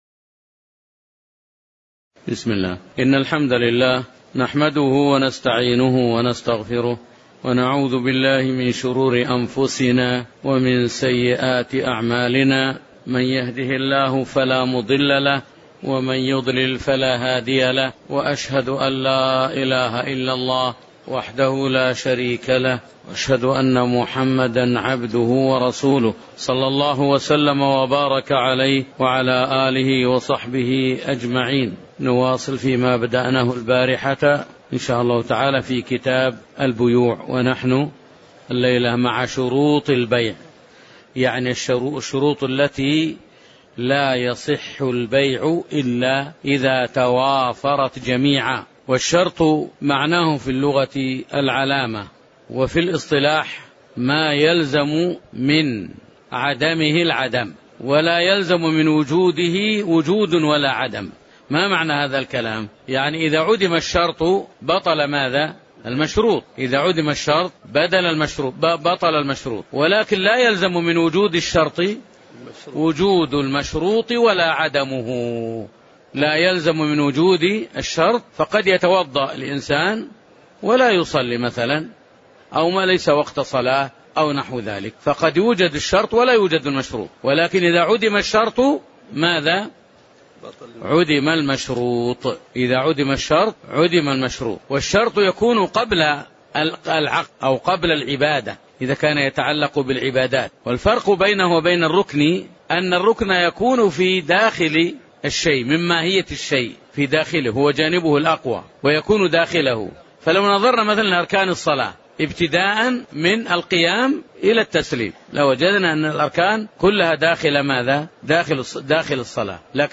تاريخ النشر ٢ محرم ١٤٤٠ هـ المكان: المسجد النبوي الشيخ